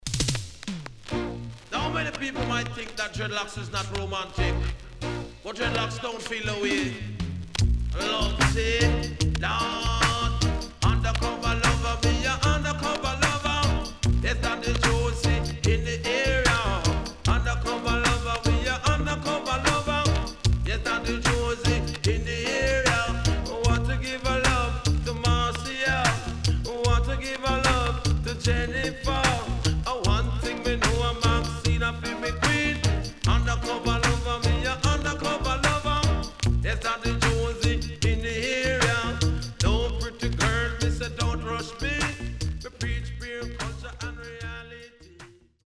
SIDE A:プレス起因により所々ノイズ入ります。